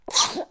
6ec12cb59e20a56deb29dfacb8daac8f1edfcc3b CosmicRageSounds / ogg / general / baby / sneeze1.ogg Gitea c7ed818377 Auto-process WAV files: 96 file(s) updated 2025-07-02 00:09:28 +00:00 11 KiB Raw History
sneeze1.ogg